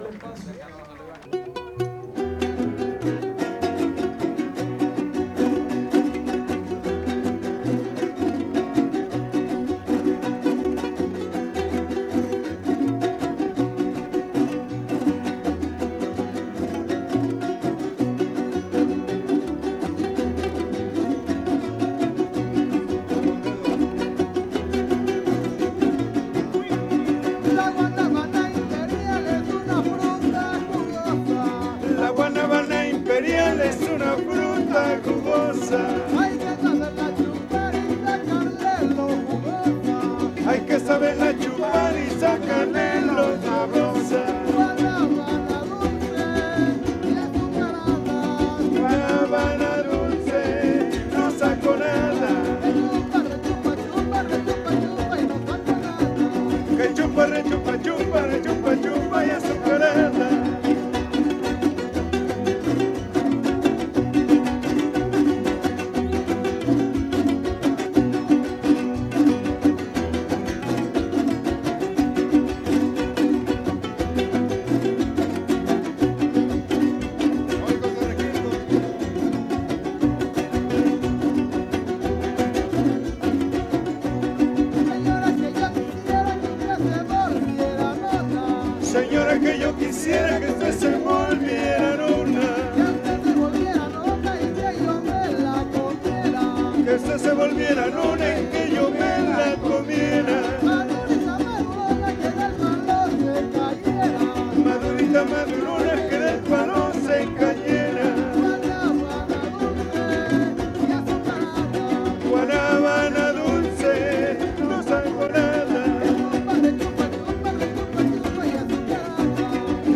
• Siquisirí (Grupo musical)
Fandango